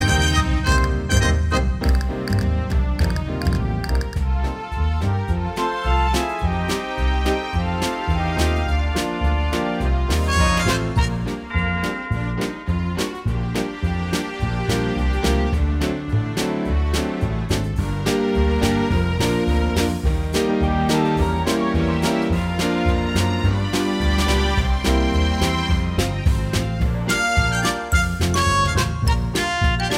For Duet Duets 2:05 Buy £1.50